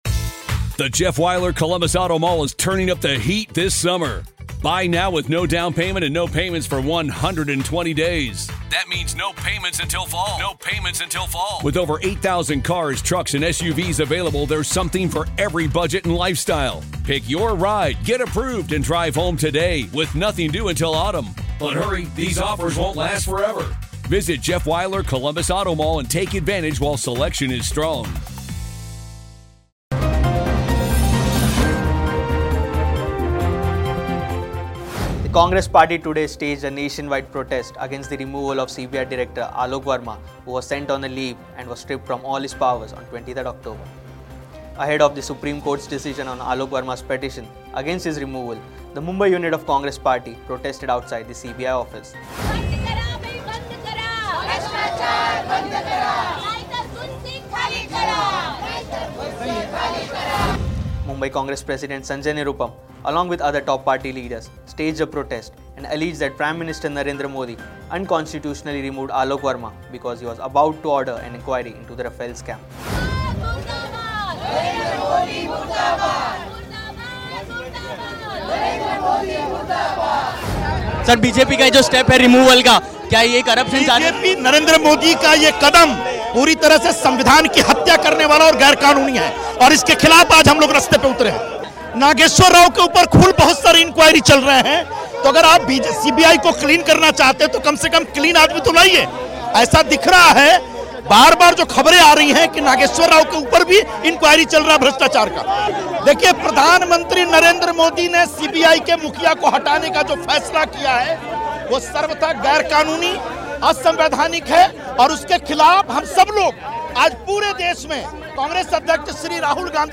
News Report